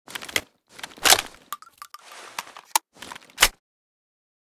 toz34_reload.ogg.bak